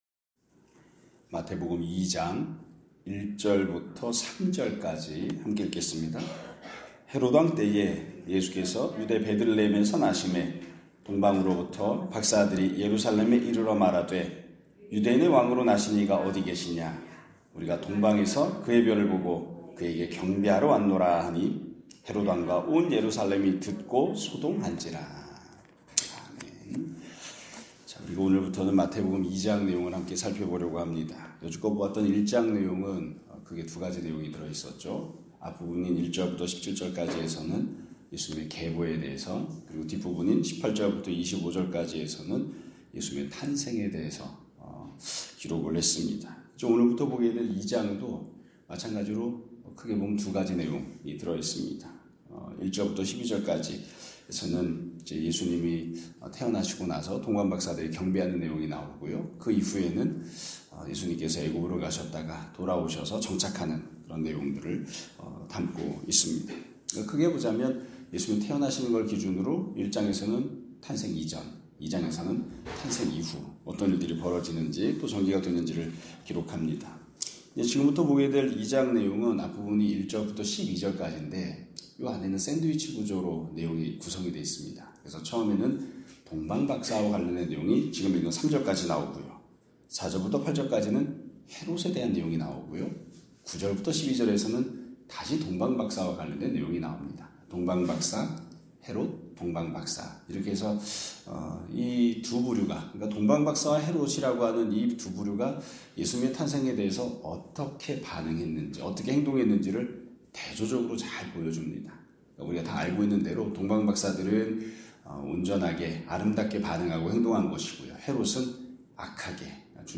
2025년 3월 31일(월요일) <아침예배> 설교입니다.